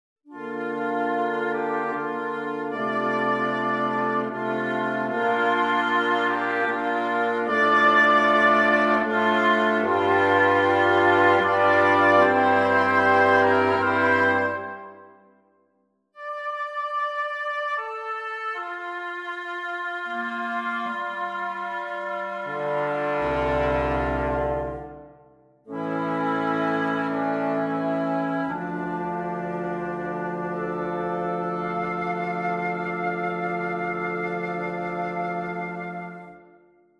beautifully sad